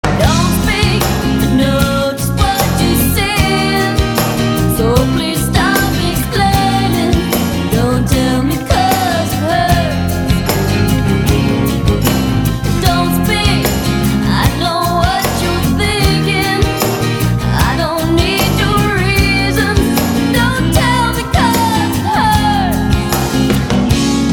Rock vocal ударные Ура!